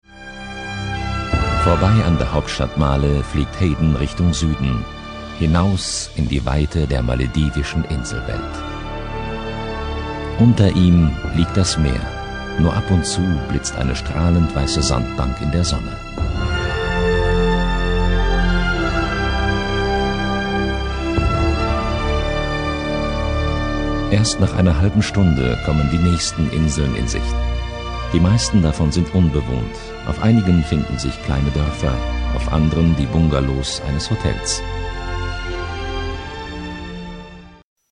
Prägnante tiefe Stimme mit großer Variationsbreite
Sprechprobe: eLearning (Muttersprache):
Great deep German voice